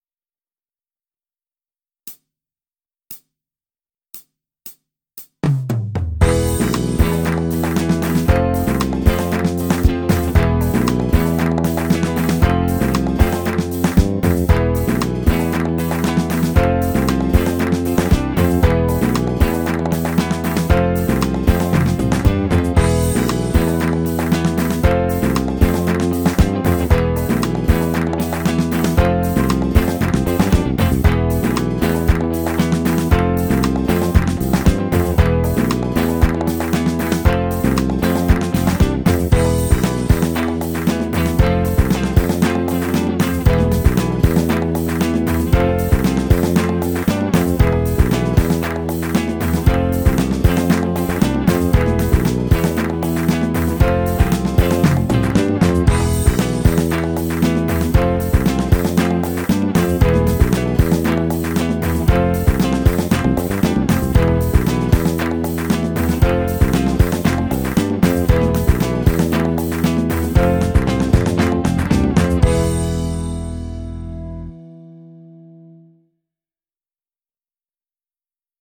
スパニッシュ8ノート・スケール ギタースケールハンドブック -島村楽器